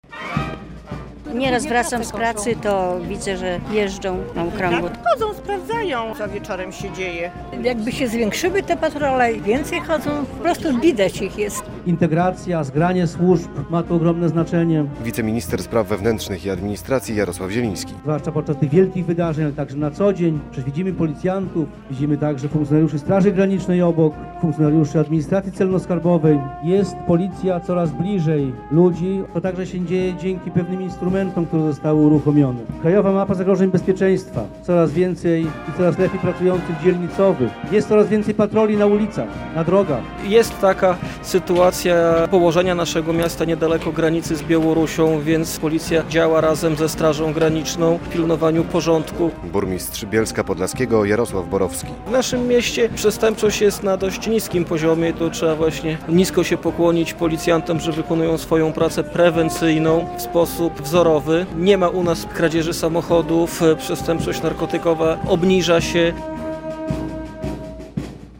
"Bóg, Honor, Ojczyzna" - te słowa widnieją na nowym sztandarze policjantów z Bielska Podlaskiego. W niedzielę (30.07) przy miejscowym ratuszu uroczyście wręczono go mundurowym.